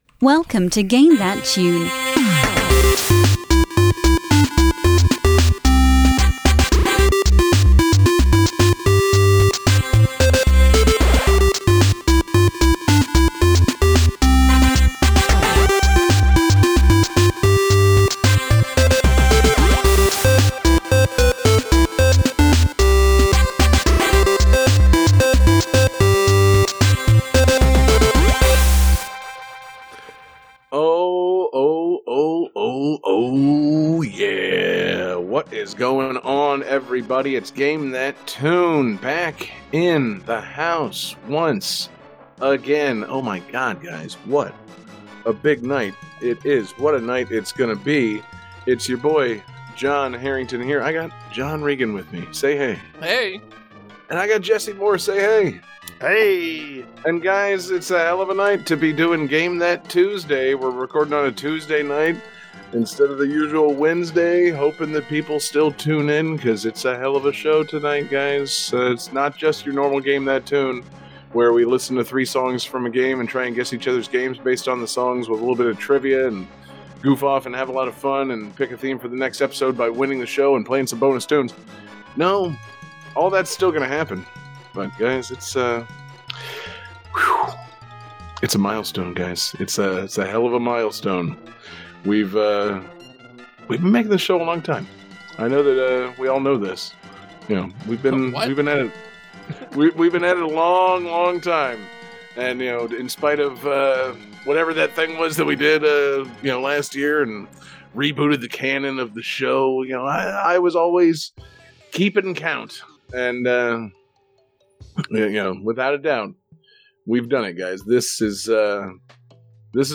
Each week, we all pick three pieces of music from a videogame, play them for the rest of the gang, and we all try to guess eachother's songs, then discuss the game.
Saddle up and let’s ride with an awesome farming mixtape!